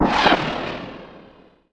RocketV2-1.wav